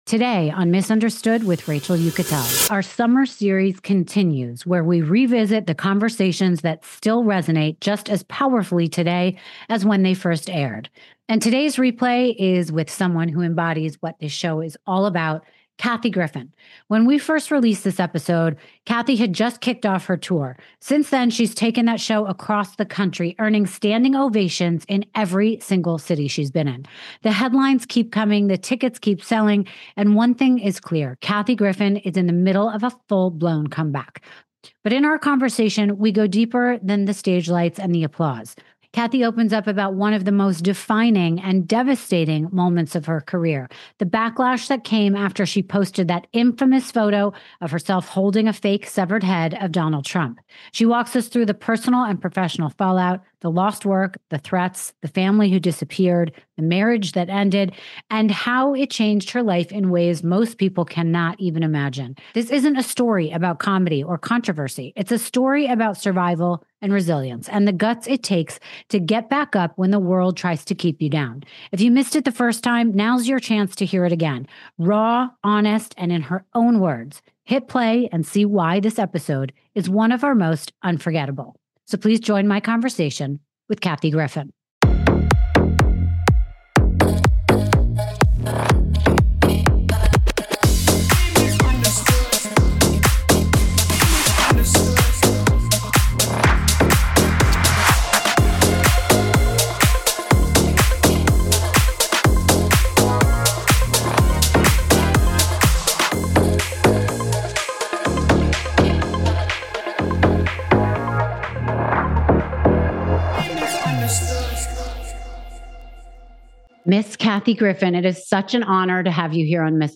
In this raw and revealing episode of Miss Understood, Rachel Uchitel sits down with comedy icon Kathy Griffin for an unflinching conversation about surviving one of the most infamous scandals in pop culture. Kathy opens up about the fallout from her controversial Trump photo, the six years she spent in professional exile, battling prescription pill addiction, surviving lung cancer, and enduring a painful divorce. Together, Rachel and Kathy swap stories of public shaming, loss, and resilience, exploring what it really takes to rebuild when the world has written you off.